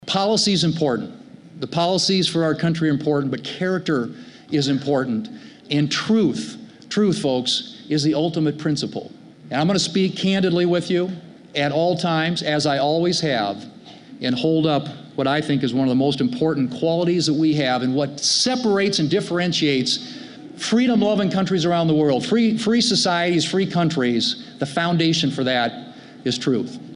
Thune told supporters he will always level with South Dakotans even if he is disagreed with and liked less.